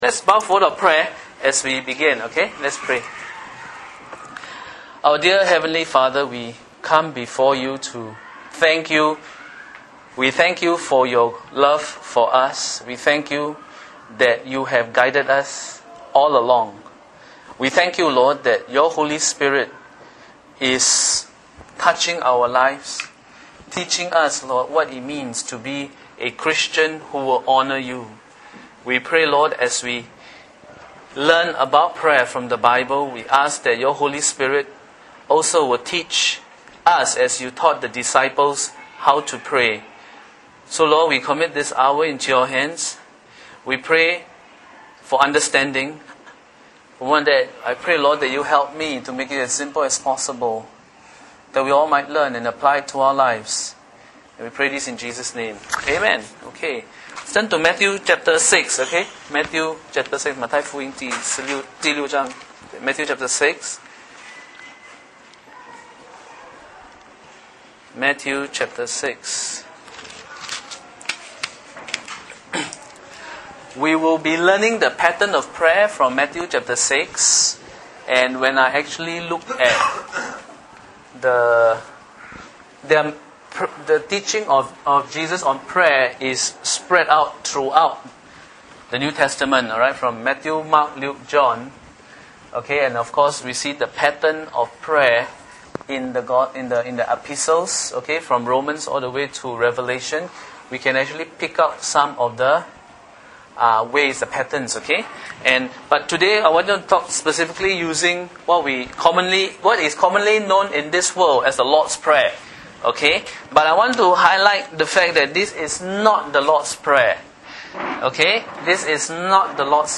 Bible Teaching on the elements of prayer